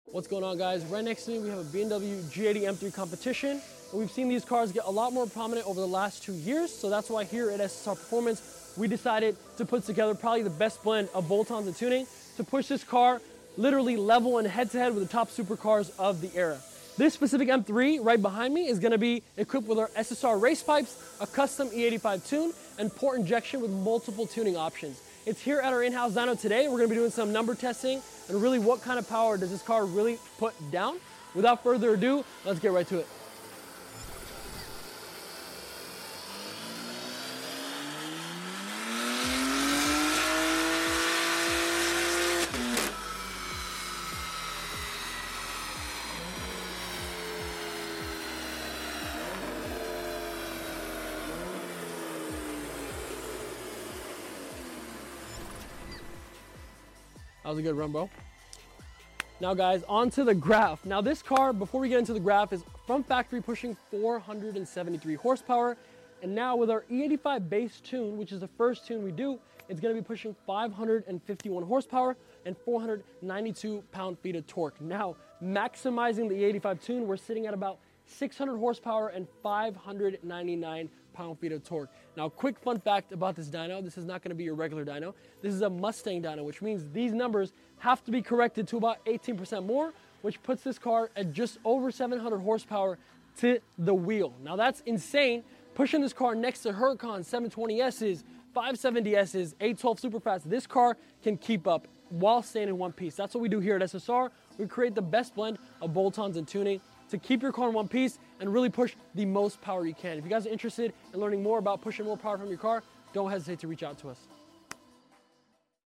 Increasing 200+ Wheel HP sound effects free download
BMW G80 M3 Competition // featuring our SSR800 Package // SSR Race Pipes // E85 Custom Tune